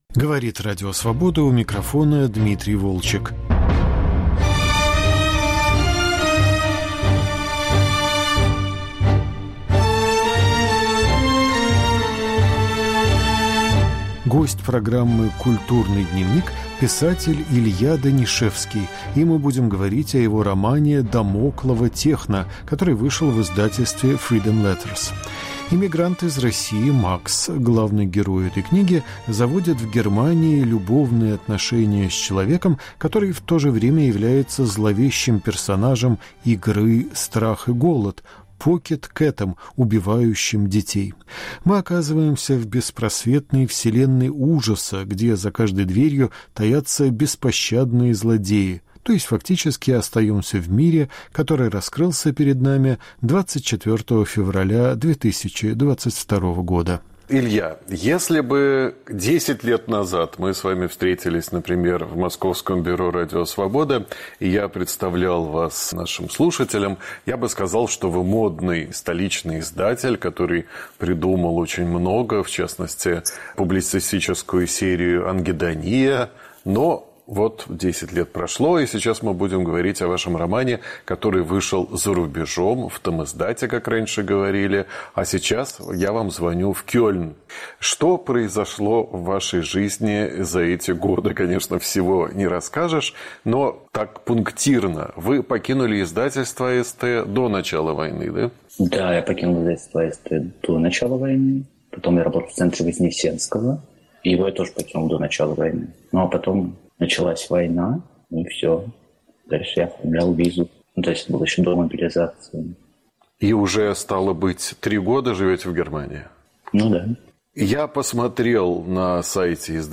Разговор о романе "Дамоклово техно"